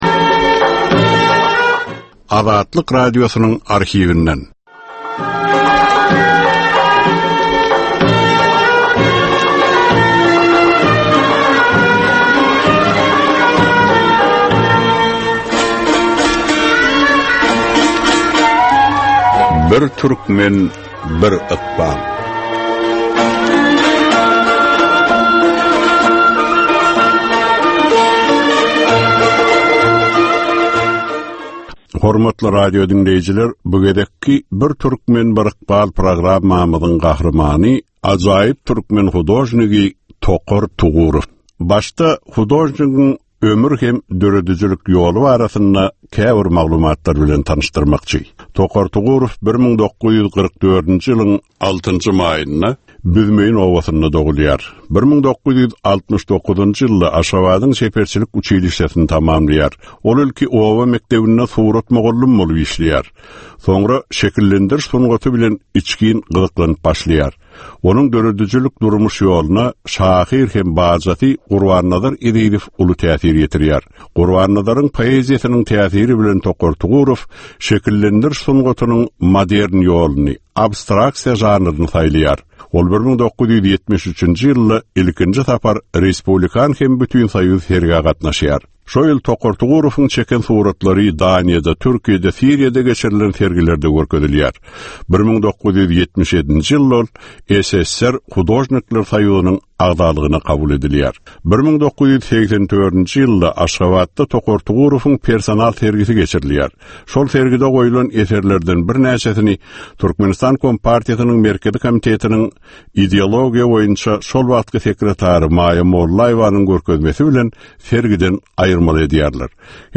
Türkmenistan we türkmen halky bilen ykbaly baglanyşykly görnükli şahsyýetlerin ömri we işi barada ýörite gepleşik. Bu gepleşikde gürrüňi edilýän gahrymanyň ömri we işi barada ginişleýin arhiw materiallary, dürli kärdäki adamlaryň, synçylaryň, bilermenleriň, žurnalistleriň we ýazyjy-şahyrlaryň pikirleri, ýatlamalary we maglumatlary berilýär.